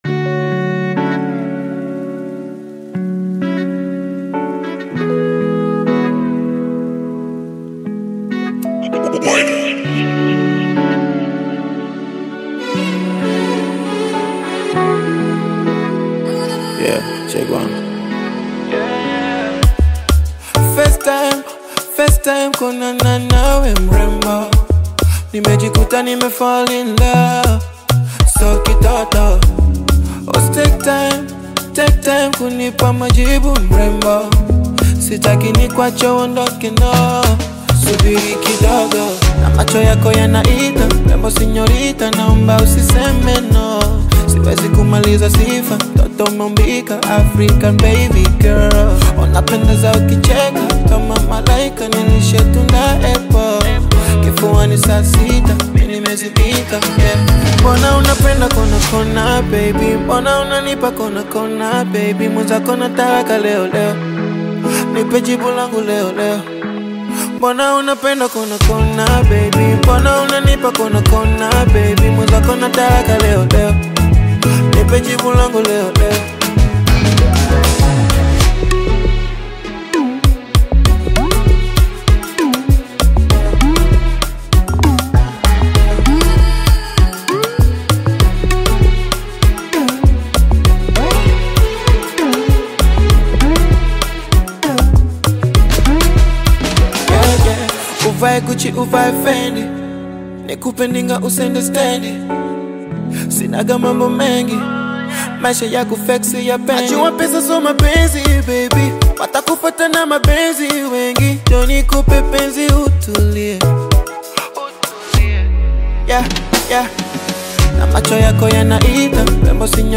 Bongo Flava music track
Tanzanian Bongo Flava artist, singer, and songwriter
Bongo Flava